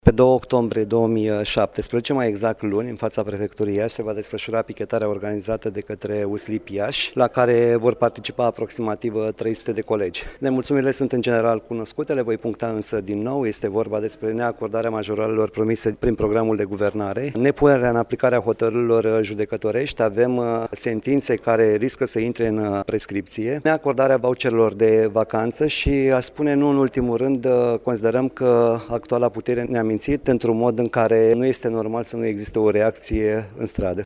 Anunţul a fost făcut, astăzi, în şedinţa Comisiei de Dialog social de la Iaşi.